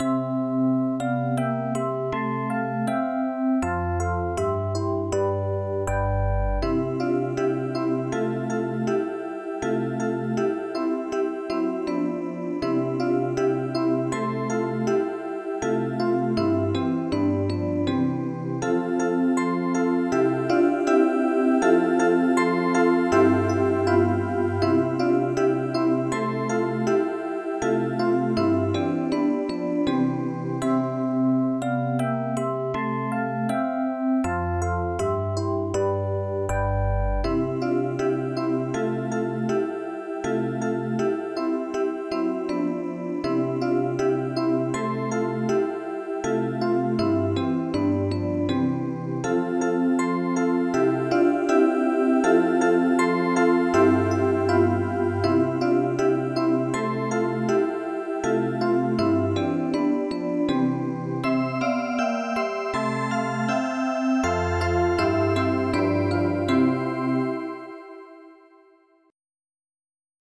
Georgian MID Songs for Children